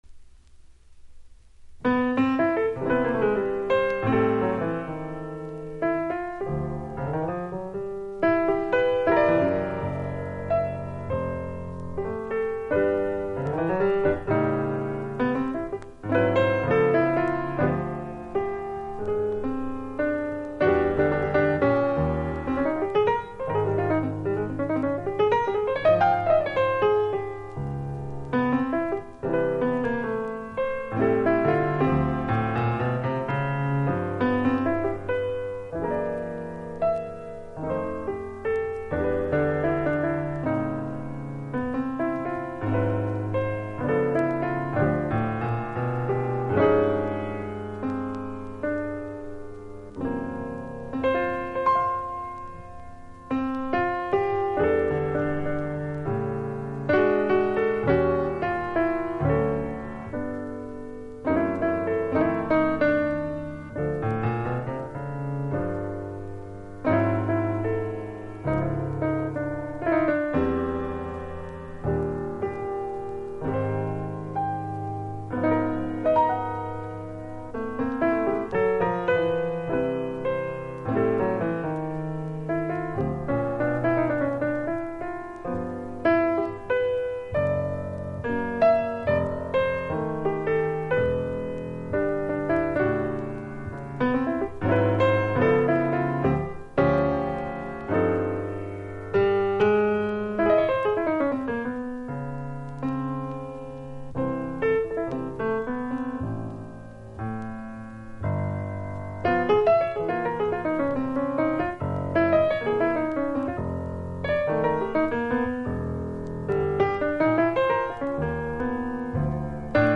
（プレス・小傷によりチリ、プチ…